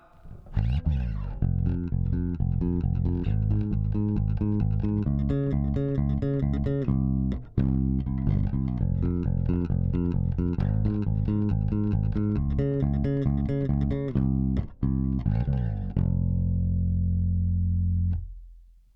ベースラインでこの曲なーんだ